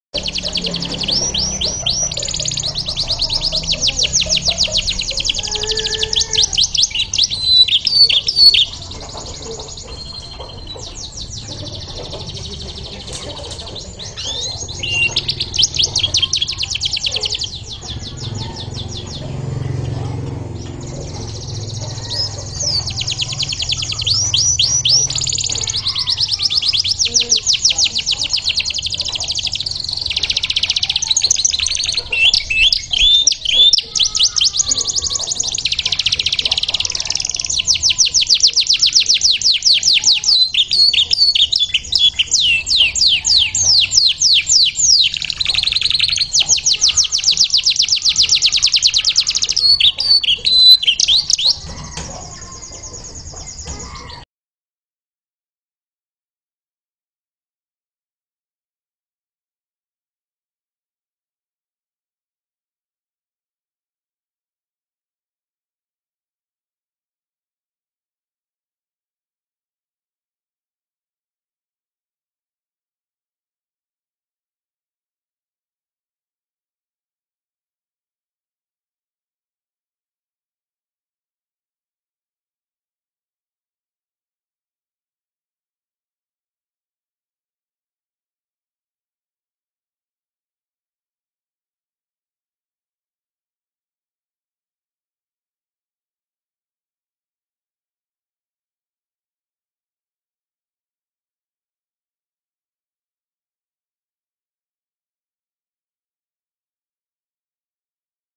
Suara Burung Kenari Isian
2. Suara Burung Kenari Isian Prenjak Ciblek Super Mewah
2.-Suara-Burung-Kenari-Isian-Prenjak-Ciblek-Super-Mewah.mp3